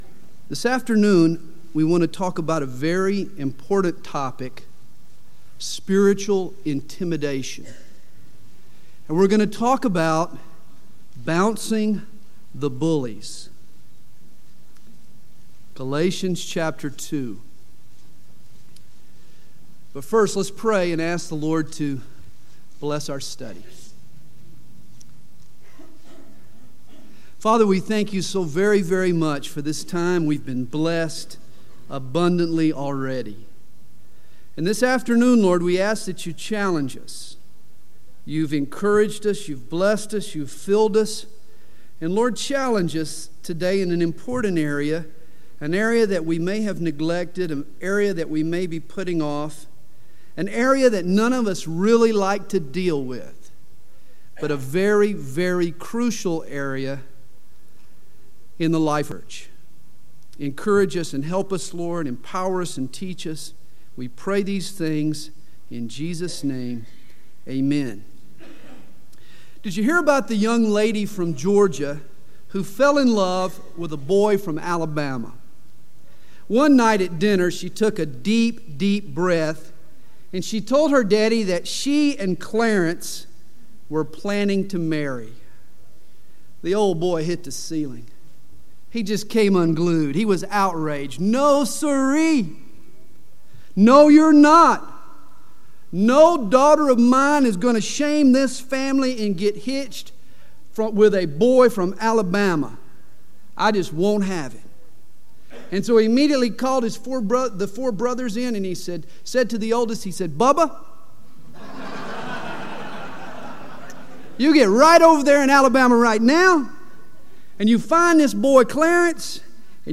Home » Sermons » 1999 DSPC: Session 8